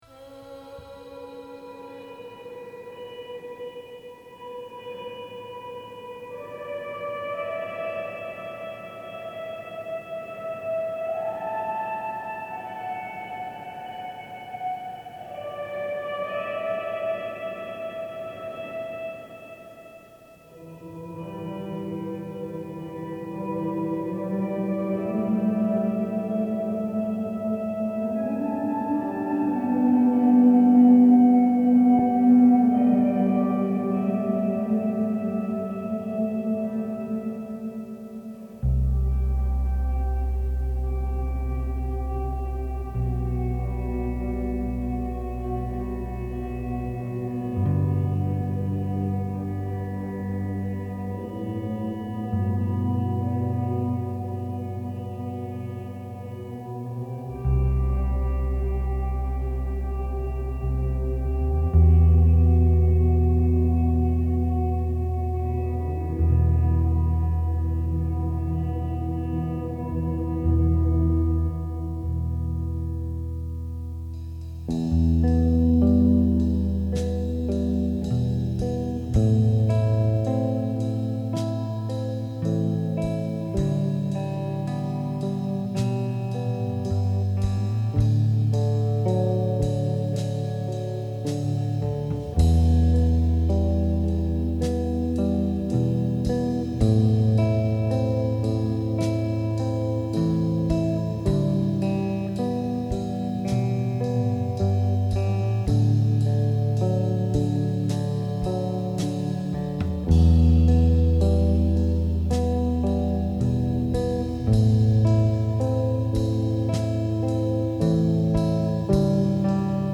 El biorritmo del alma caligrafiado a cámara lenta.